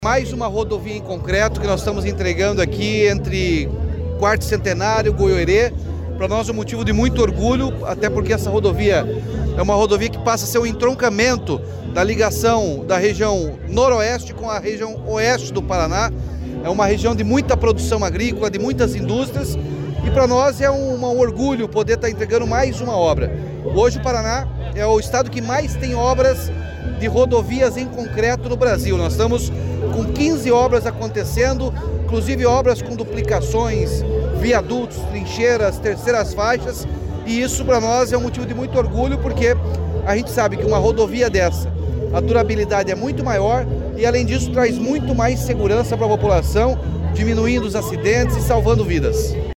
Sonora do governador Ratinho Junior sobre a pavimentação em concreto da PR-180